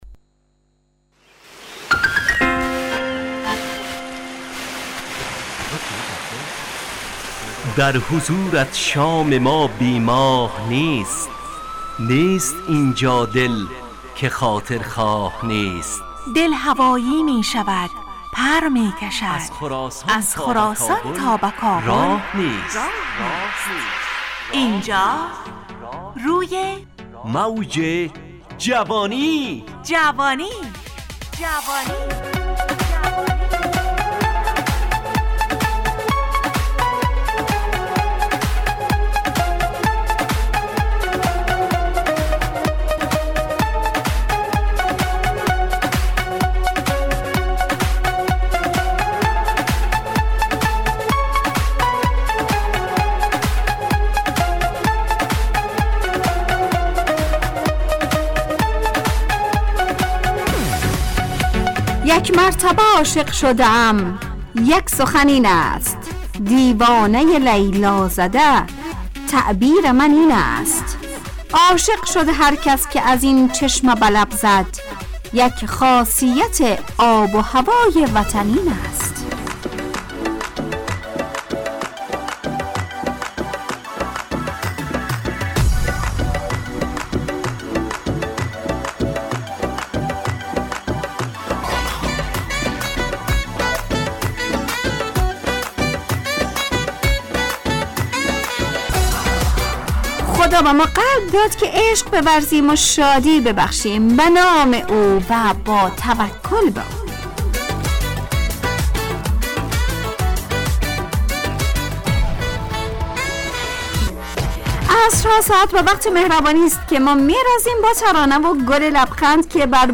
روی موج جوانی، برنامه شادو عصرانه رادیودری.
همراه با ترانه و موسیقی مدت برنامه 70 دقیقه .